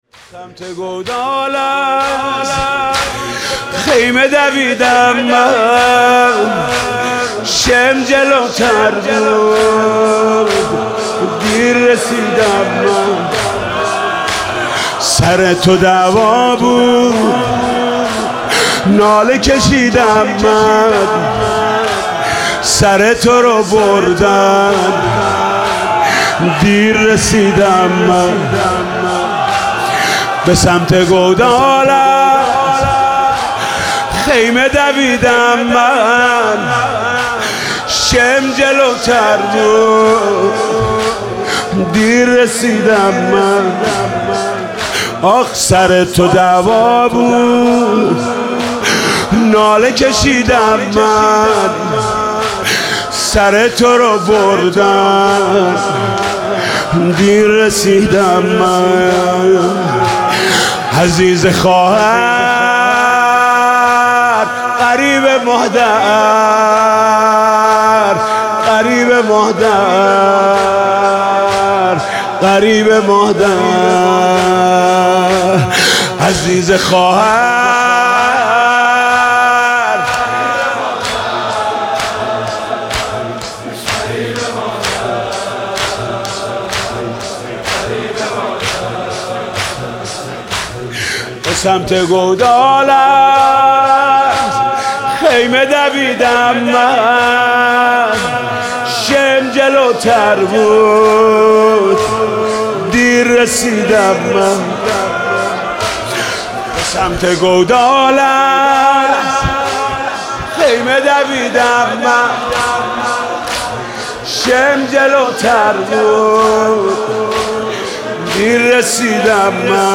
حاج محمود کریمی
فاطمیه 97
فاطمیه97 شب پنجم شور محمود کریممی